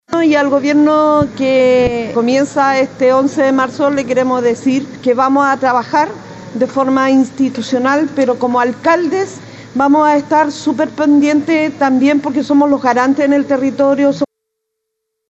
La alcaldesa de La Pintana, Claudia Pizarro, señaló que los alcaldes serán los primeros en levantarse a defender cualquier posible retroceso que afecte a las personas en las distintas comunas del país.